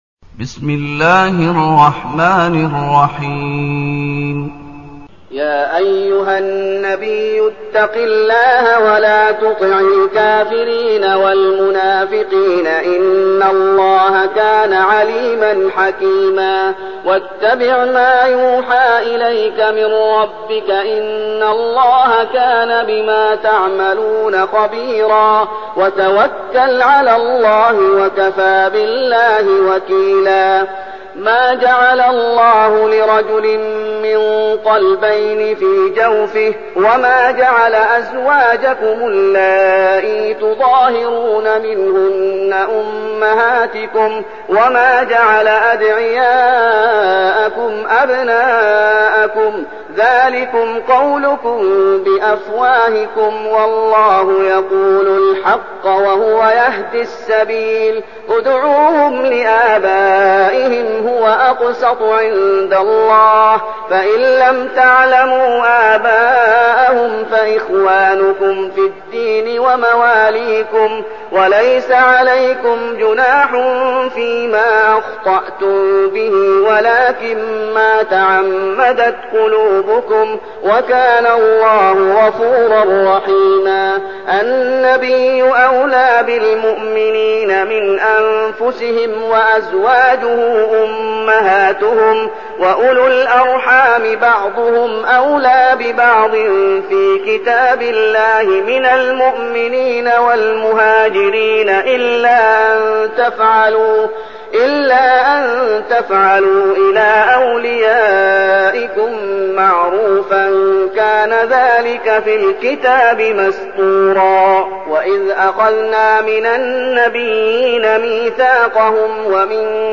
المكان: المسجد النبوي الشيخ: فضيلة الشيخ محمد أيوب فضيلة الشيخ محمد أيوب الأحزاب